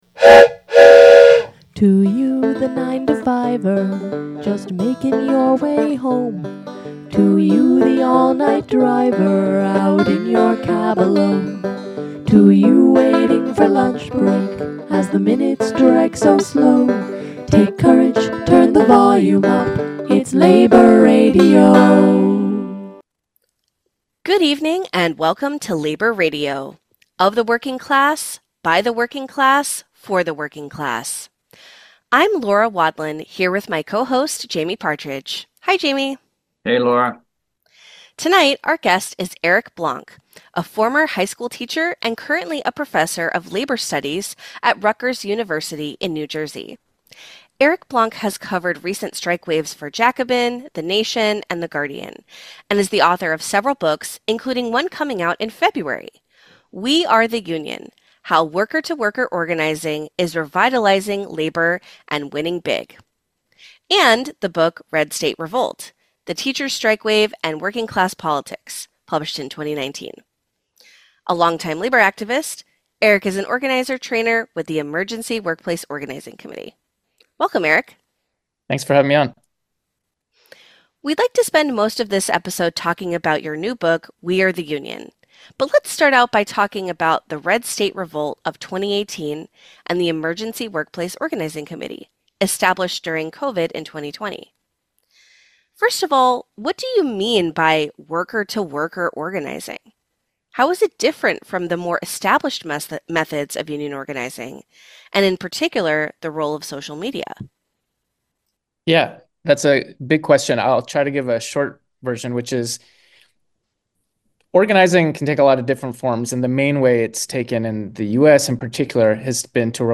In this interview, he talks about how recent struggles have developed a new organizing model, worker-to-worker unionism, which builds scalable power by giving rank-and-filers an unprecedented degree of leadership. Through digital tools and ambitious campaigns, young worker leaders are turning the labor movement back into a movement—and they're winning.